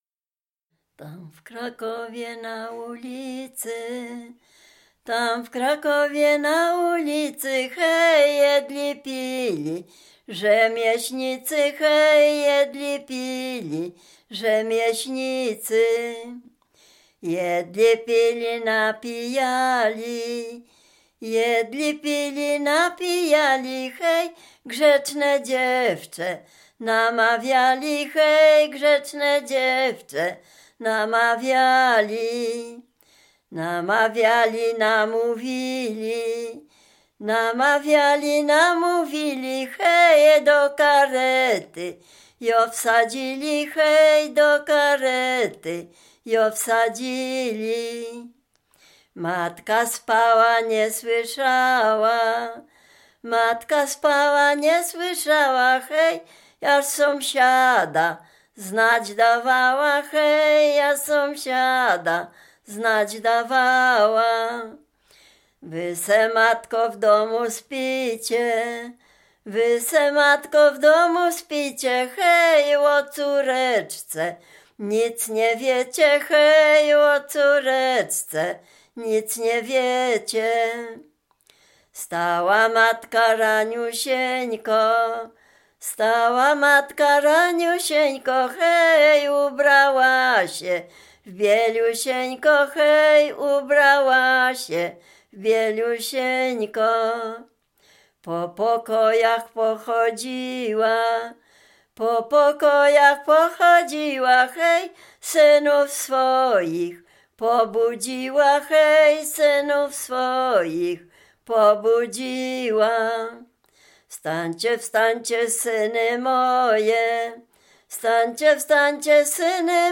Dolny Śląsk, powiat bolesławiecki, gmina Nowogrodziec, wieś Zebrzydowa
Ballada
ballady dziadowskie